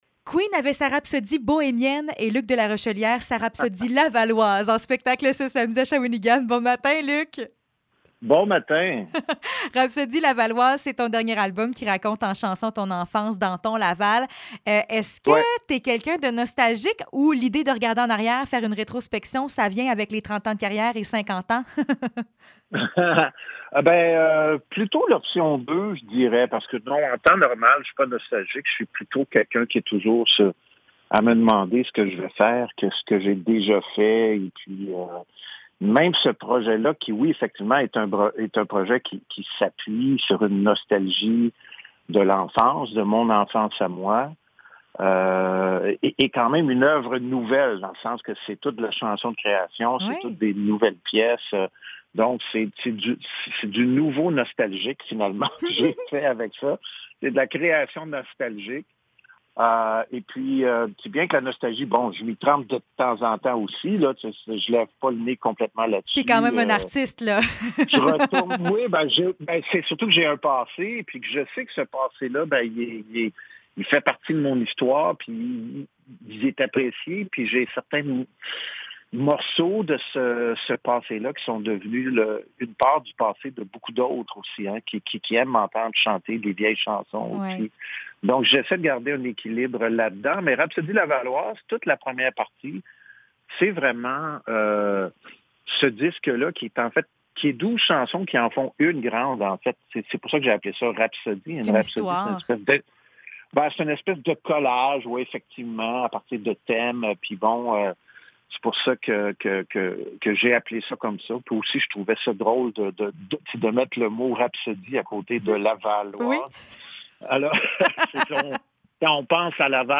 Entrevue avec Luc de Larochellière